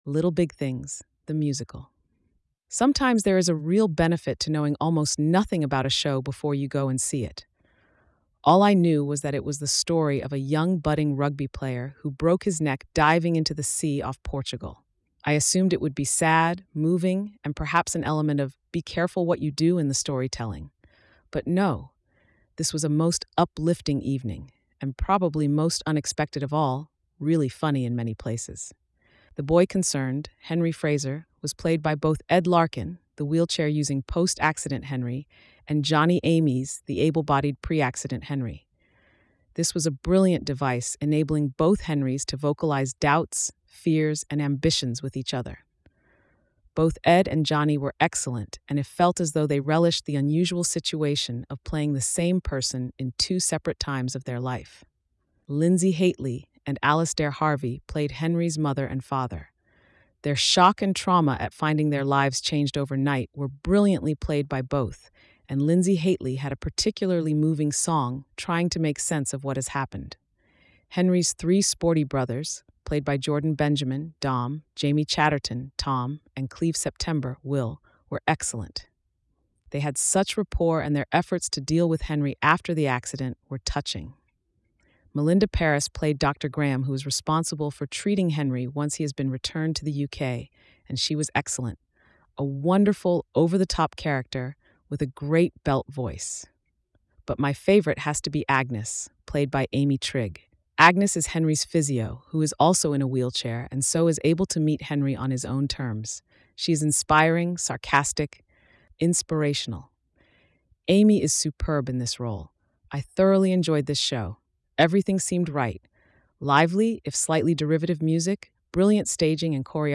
Little-Big-Things--Narration-.mp3